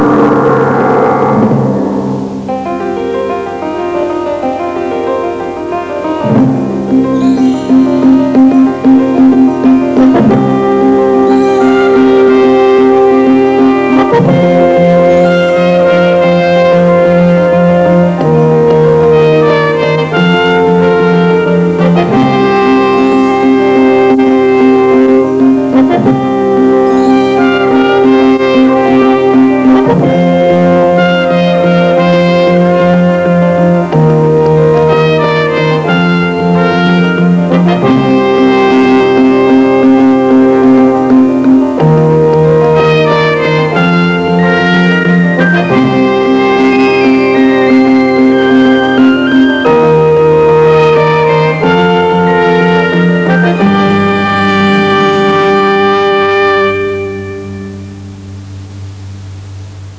closing theme